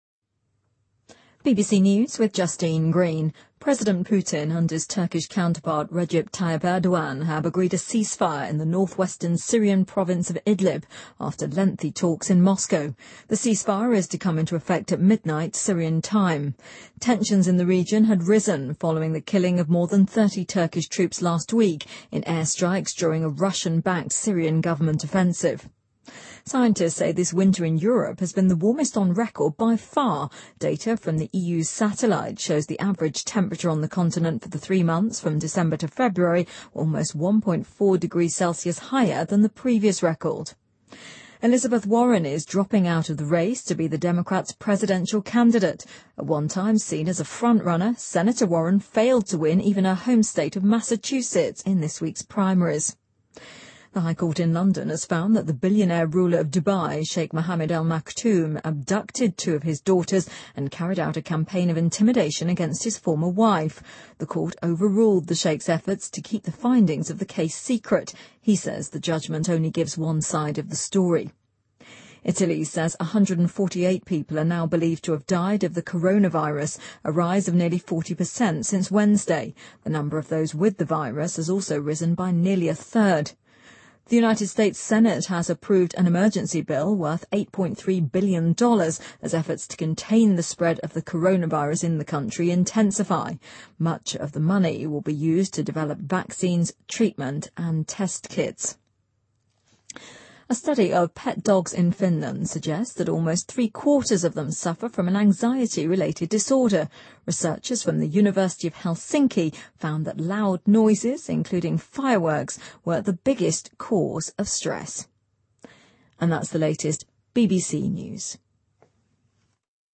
英音听力讲解:美参院通过83亿美元法案支援抗疫